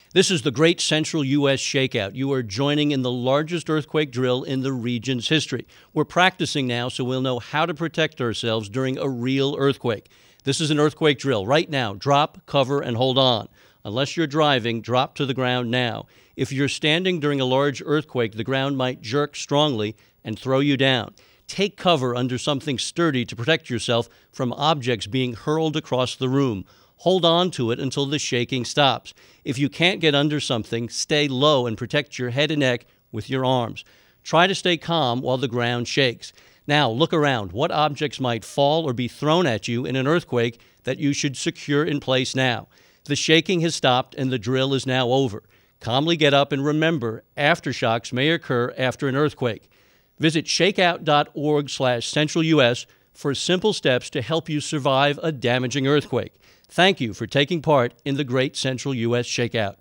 The Great Central U.S. ShakeOut - Download: Drill Narration Only, AIFF Format
ShakeOut_60sec_Drill_Broadcast_English_No_Sound_Effects.aiff